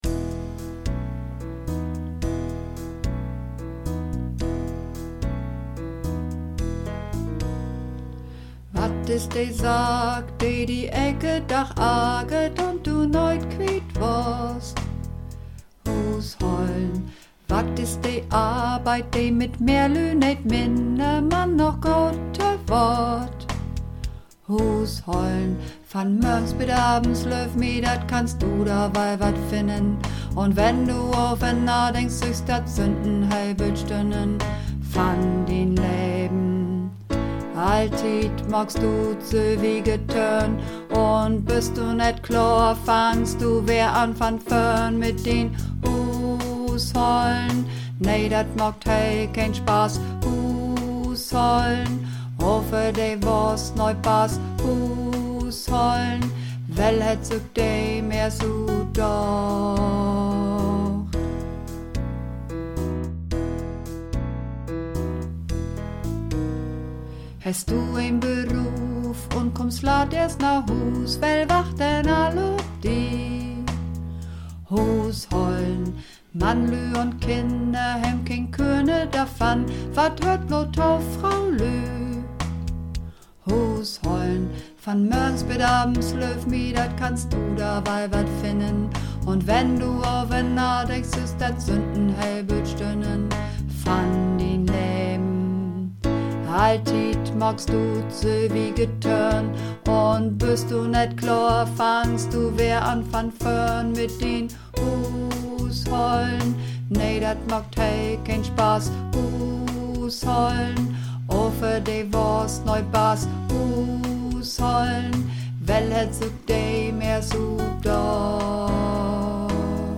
Übungsaufnahmen - Huushollen
Runterladen (Mit rechter Maustaste anklicken, Menübefehl auswählen)   Huushollen (Tief)